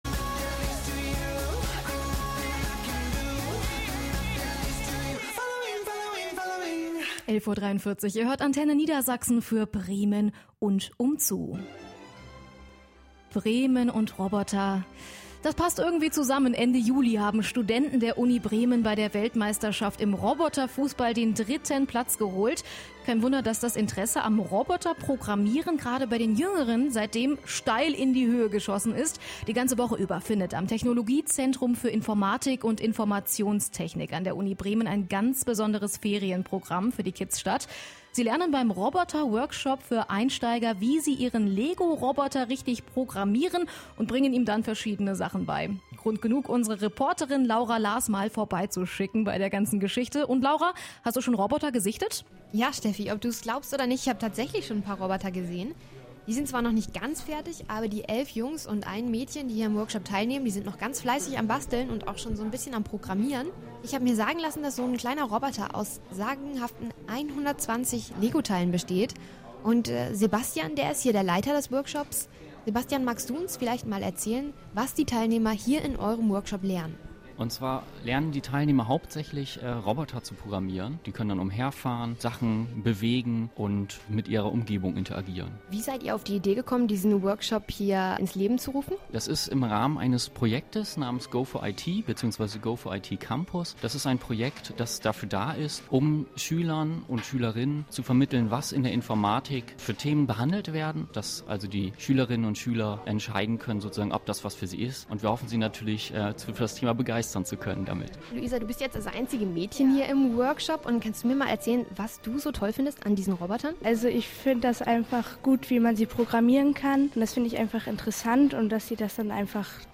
Radio Antenne war am 15.08.2014 zu Besuch und hat einen Radio Beitrag zum Workshop erstellt.